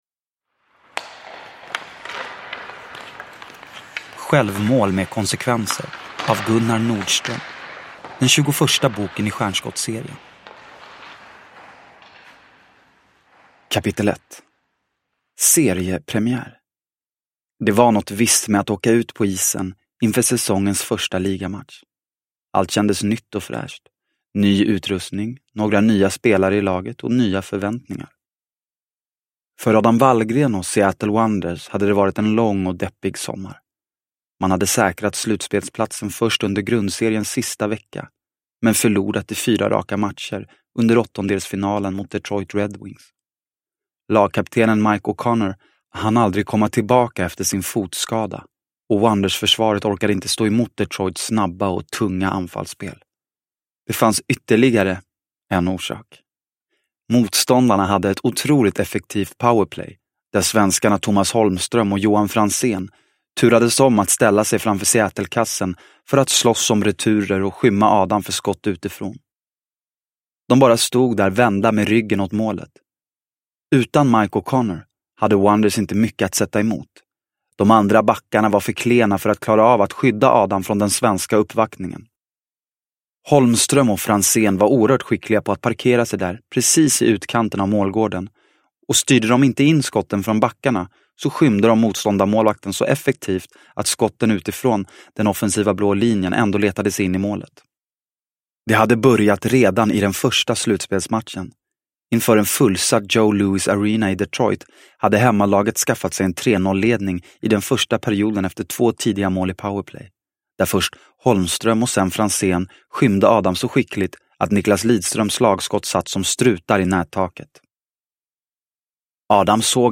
Självmål med konsekvenser – Ljudbok – Laddas ner